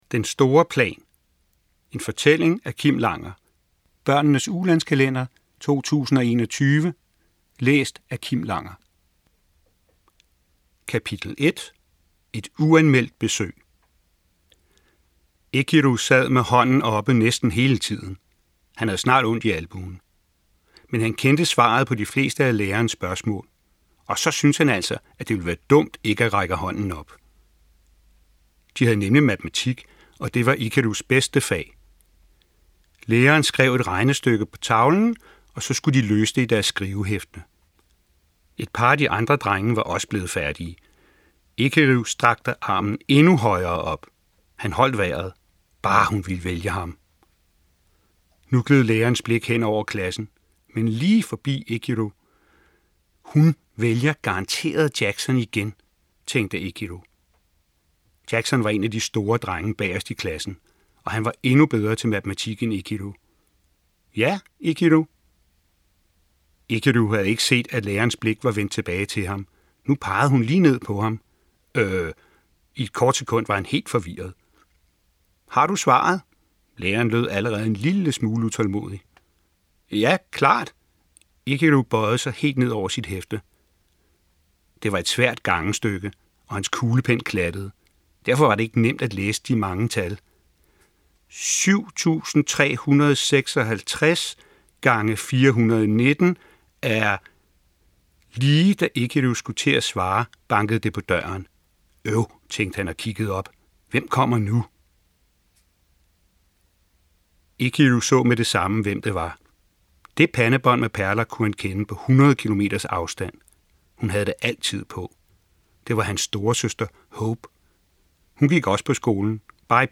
Download lydbogen - Den store Plan